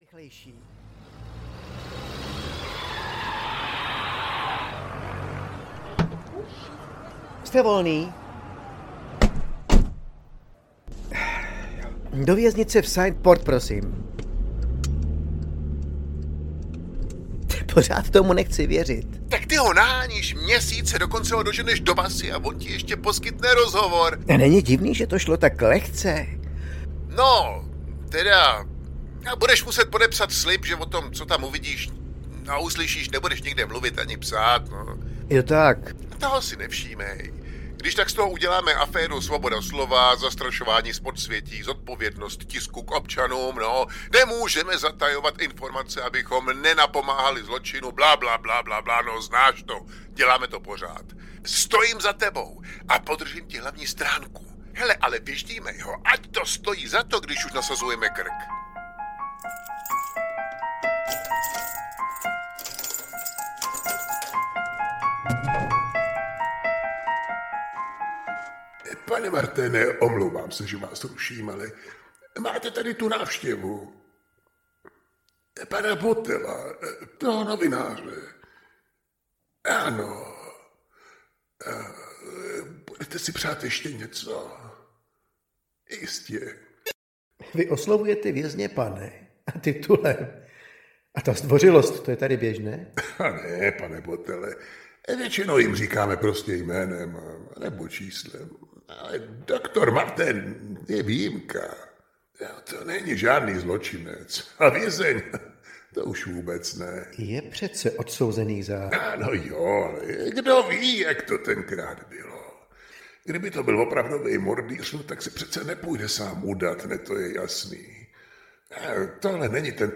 Ukázka z knihy
Klavírní doprovod
Audio nahráno ve studio ABSOLON